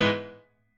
admin-leaf-alice-in-misanthrope/piano34_1_005.ogg at main